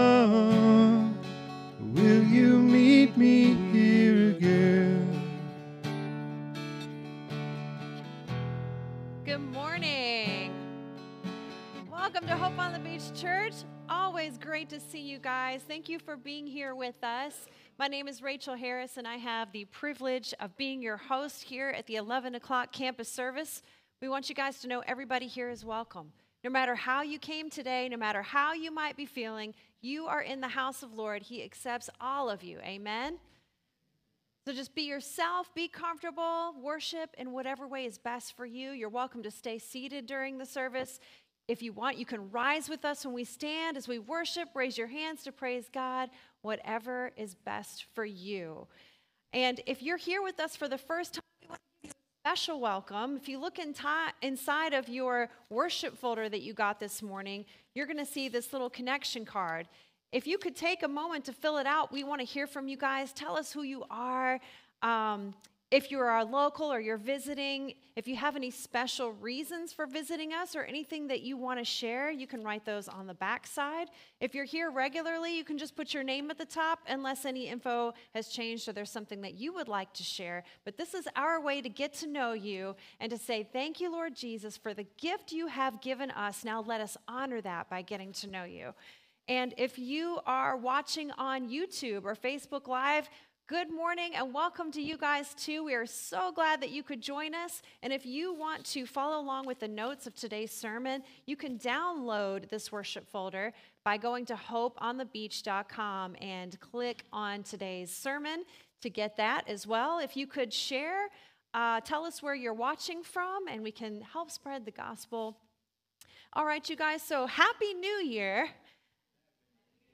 SERMON DESCRIPTION We journey back to the early days of the Christian Church with insights from the Book of Acts to explore the question, "what is the church?"